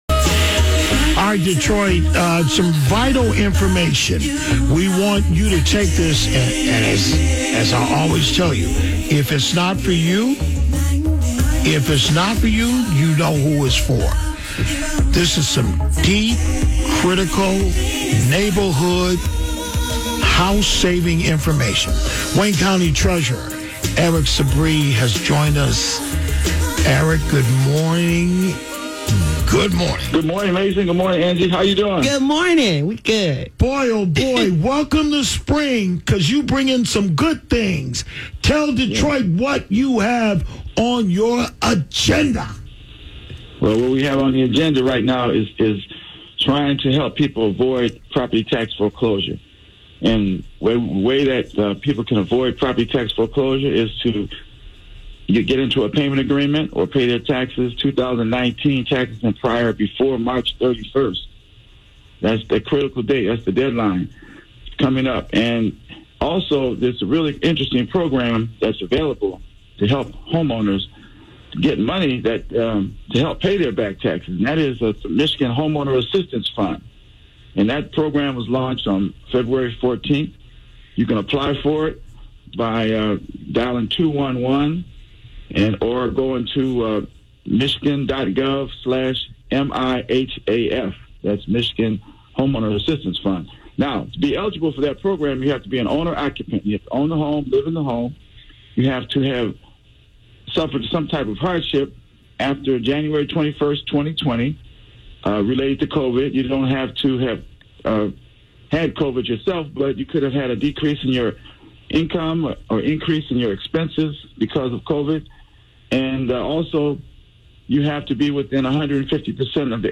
Interviews
wdmk_wct_aircheck.mp3